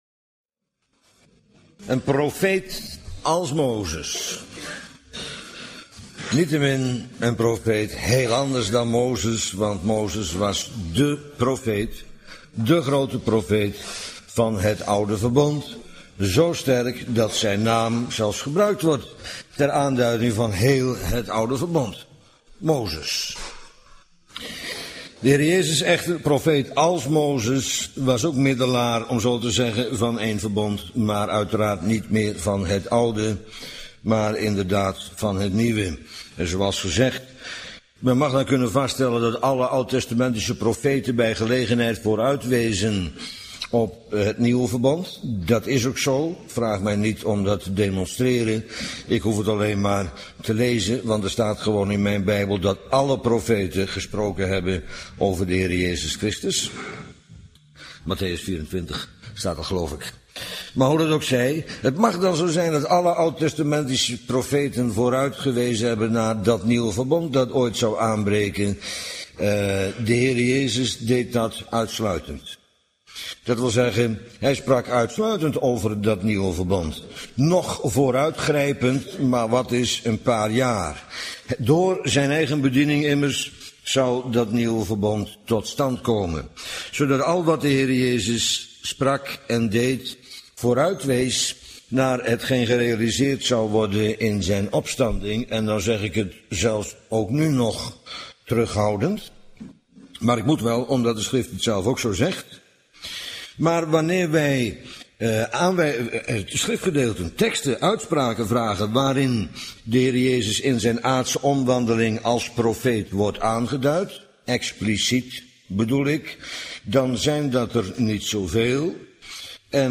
Bijbelstudie lezingen op mp3.